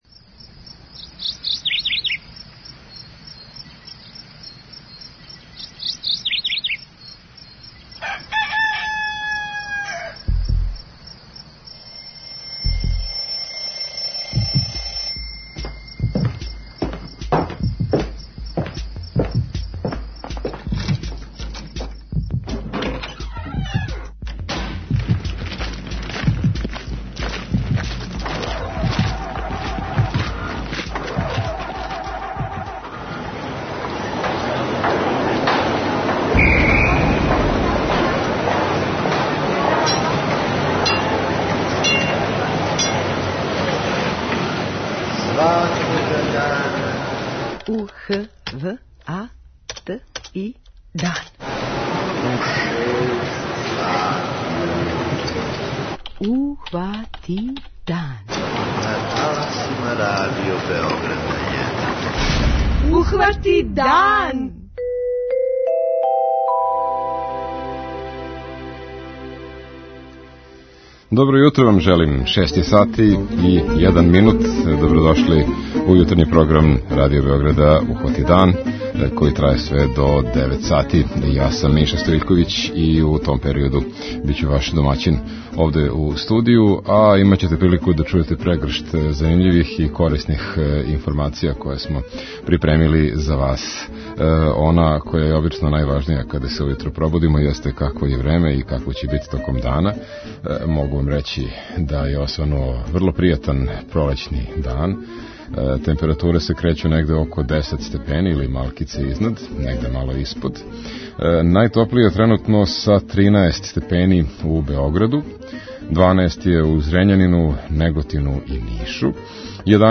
преузми : 32.37 MB Ухвати дан Autor: Група аутора Јутарњи програм Радио Београда 1!